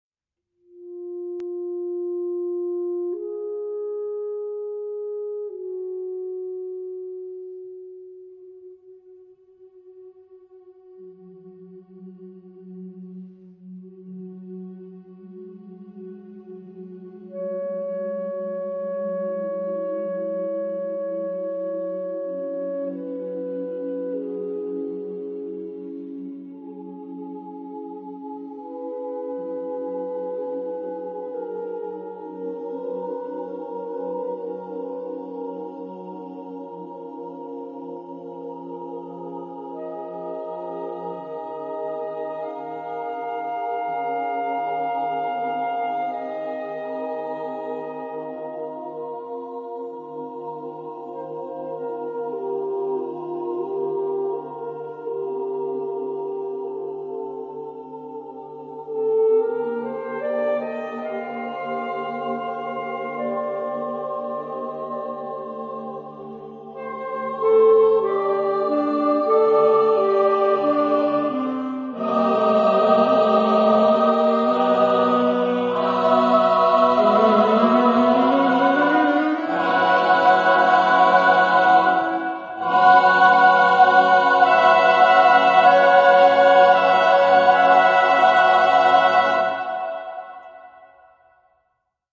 Text in: onomatopoeia
Genre-Style-Form: Secular ; Contemporary
Mood of the piece: contrasted ; rhythmic
Type of Choir: SATB  (4 mixed voices )
Instrumentation: Alt saxophone  (1 instrumental part(s))